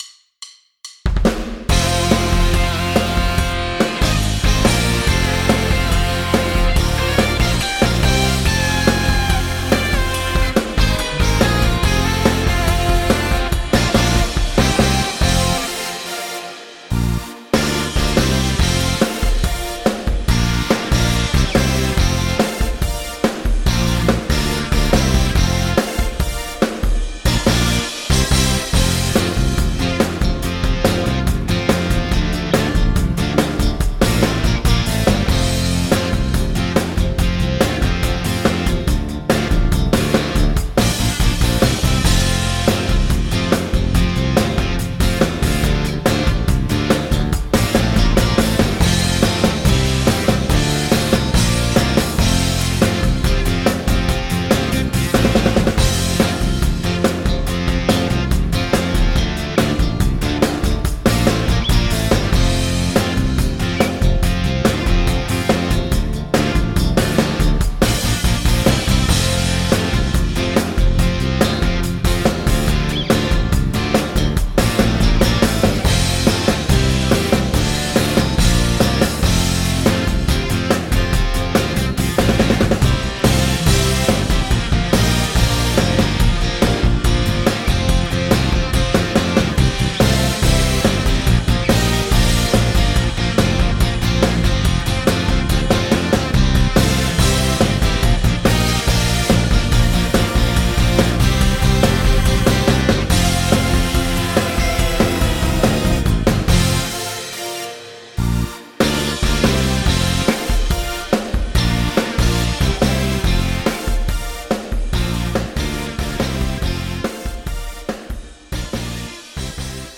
karaoké, instrumental